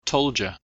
told you（トールドユー)　→　（トージャ）